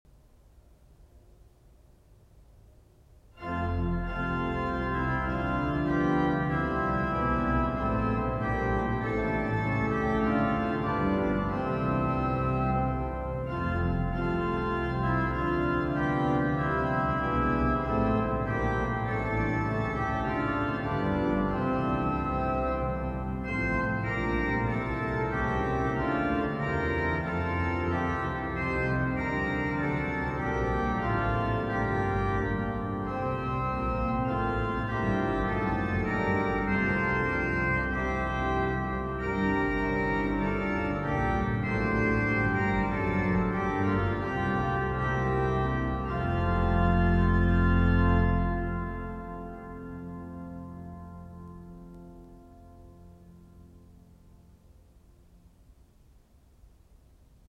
Mottolied_-_Orgelsatz_mit_Solostimme.mp3